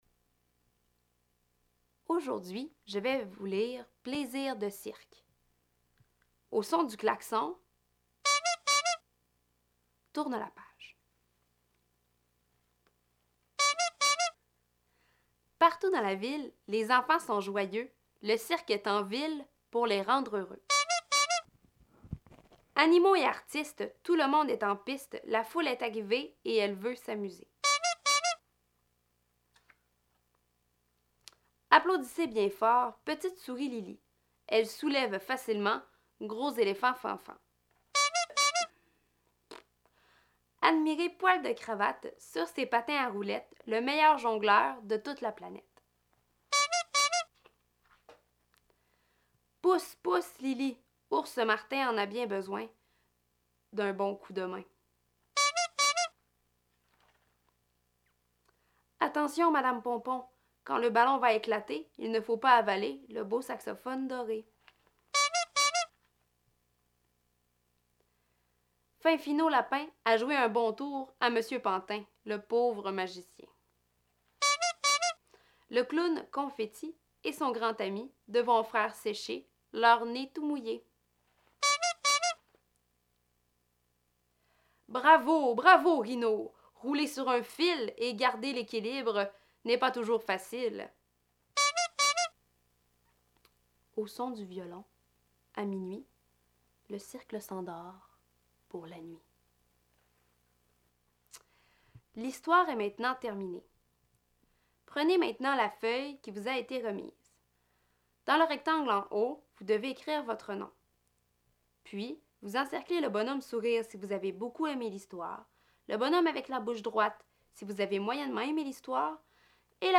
Durant le mois de novembre, l’un des cinq ateliers de la semaine était l’écoute d’un livre, préalablement enregistré, en équipe de trois ou de quatre.
• Le responsable du livre devait tourner les pages au son du klaxon et il pouvait essayer de suivre les mots avec son doigt.